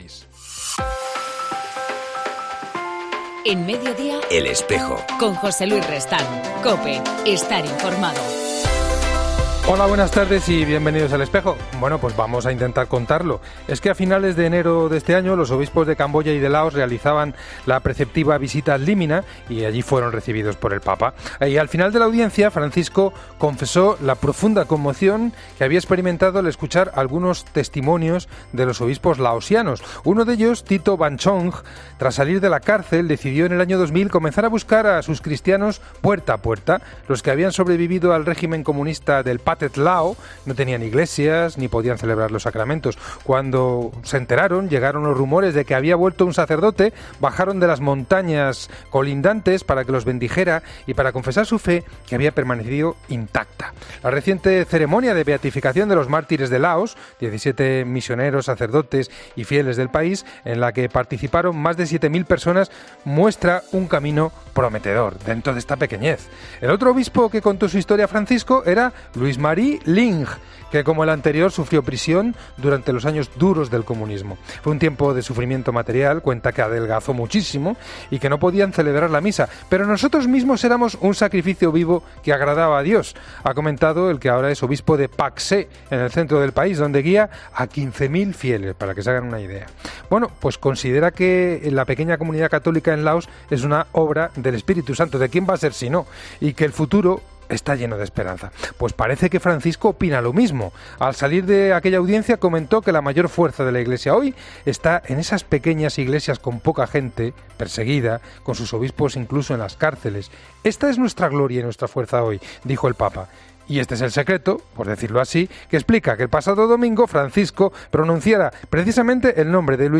En El Espejo del 24 de mayo entrevistamos a Mons. Ginés García Beltrán, obispo de la Diócesis de Guadix